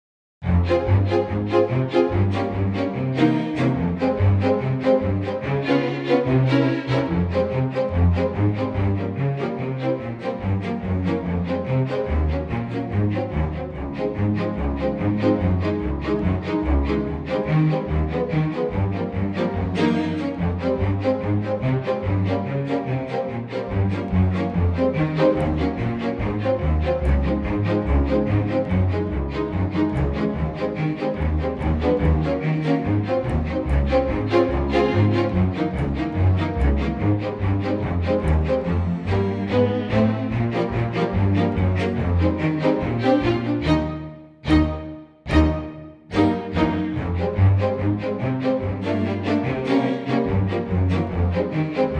key - Ab - vocal range - C to Eb
Stunning and intimate strings only arrangement
very bright in tempo but it works so well.
-Unique Backing Track Downloads